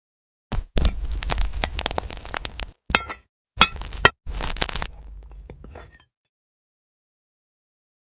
Slicing a Luxury Glass Cake… sound effects free download